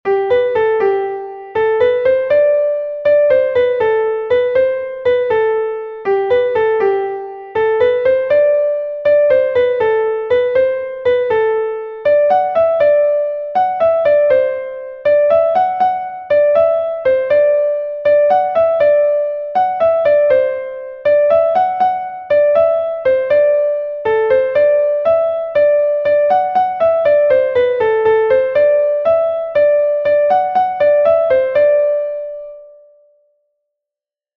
Gavotte from Brittany